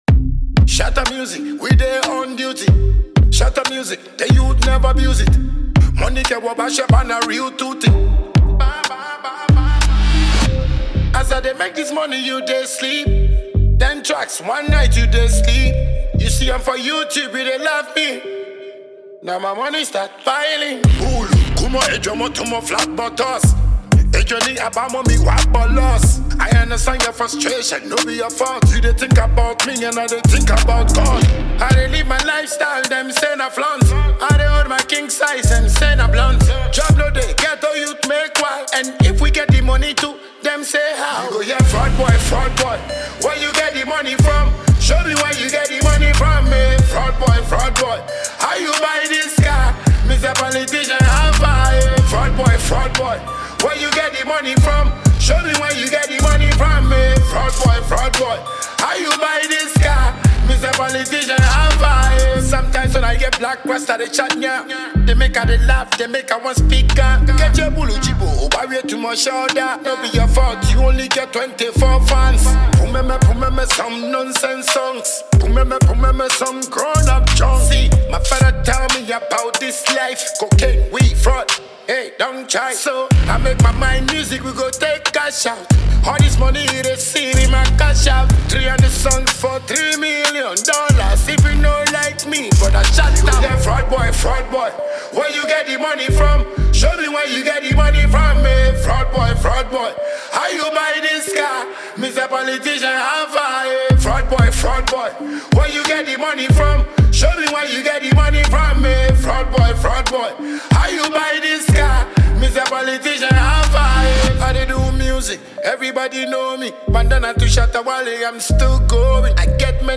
commanding vocal delivery
• Genre: Dancehall